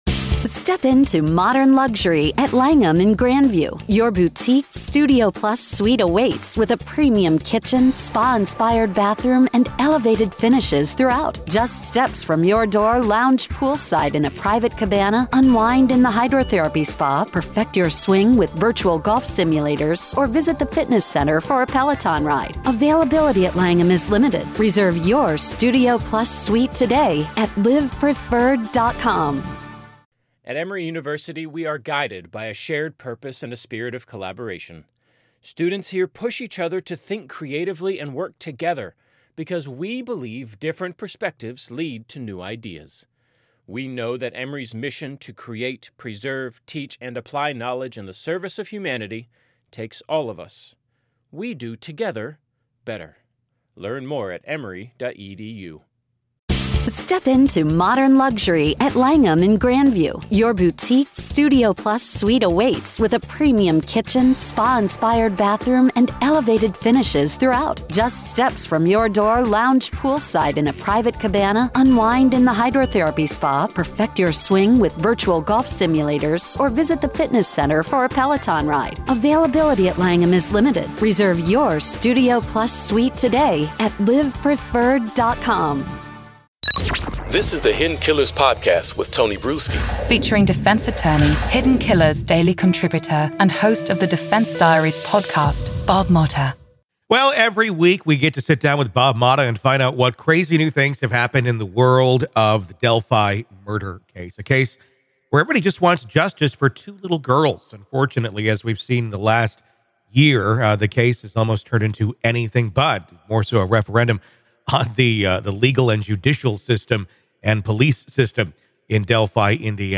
The conversation begins with an examination of two pivotal actions addressed by the Supreme Court of Indiana.